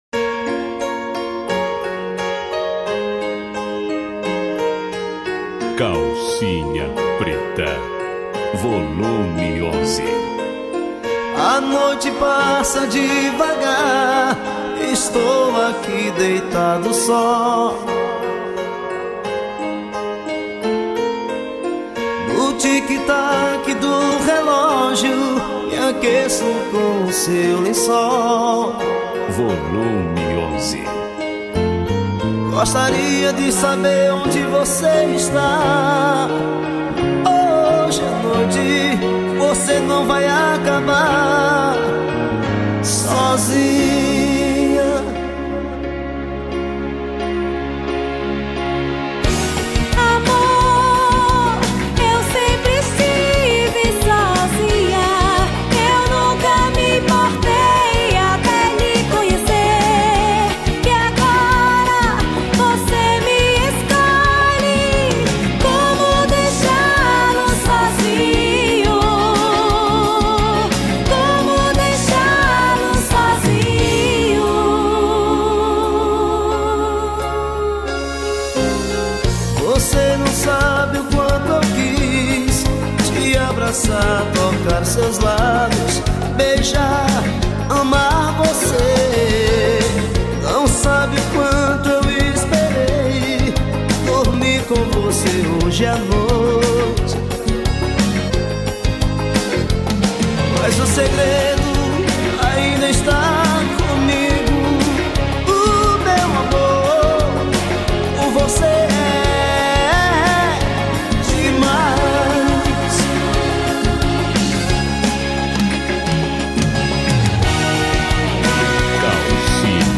2024-12-29 10:20:52 Gênero: Forró Views